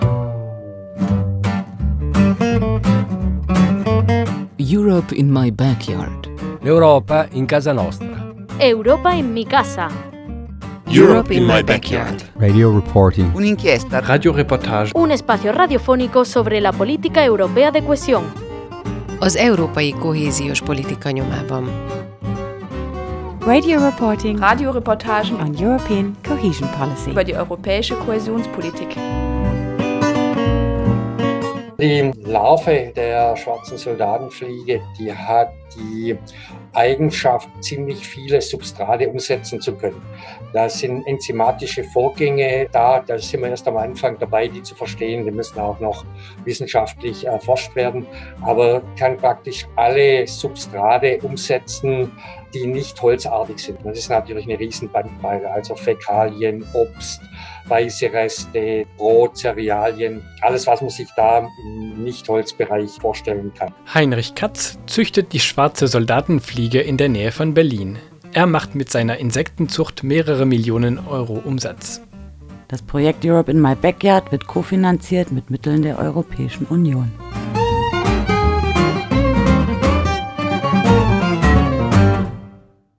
Mini-Podcast mit O-Ton